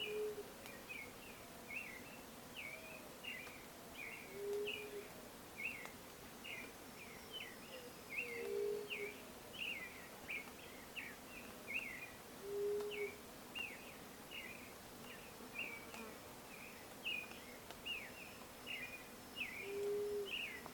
Yerutí Colorada (Leptotila rufaxilla)
Nombre en inglés: Grey-fronted Dove
Localidad o área protegida: Parque Nacional Mburucuyá
Condición: Silvestre
Certeza: Vocalización Grabada